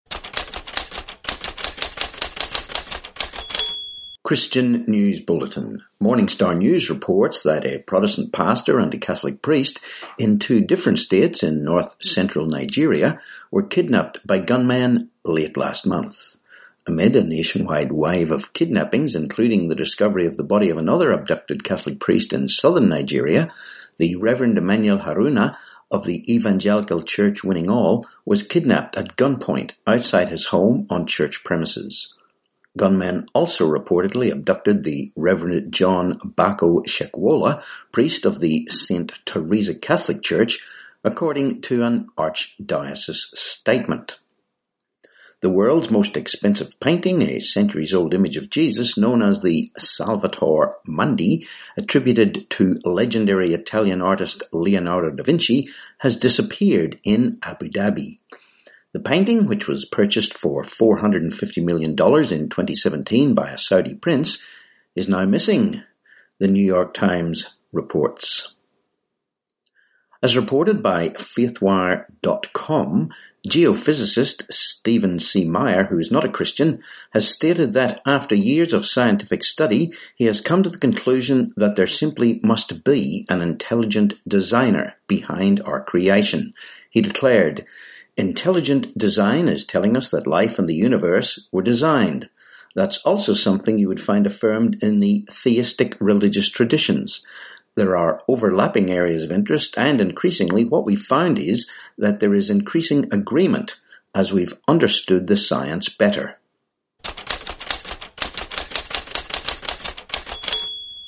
7Apr19 Christian News Bulletin